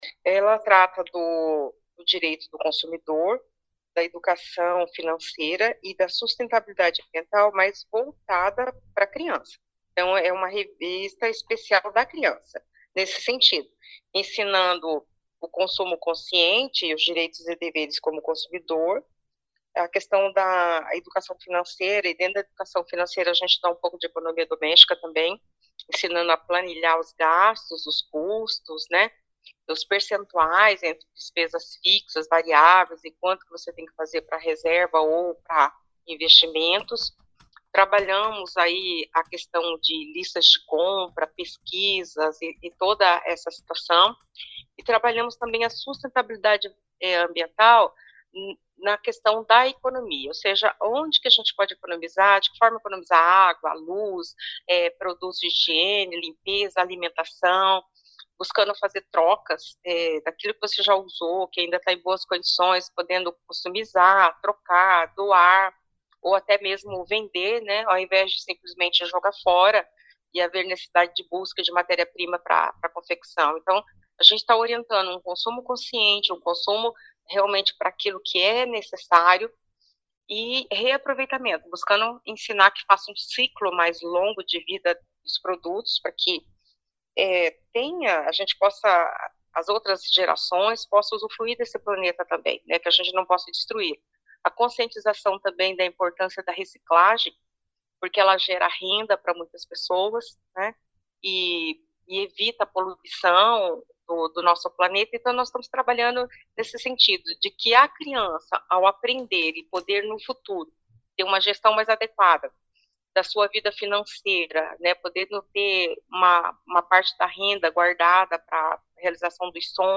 A secretária dá mais detalhes sobre a revista: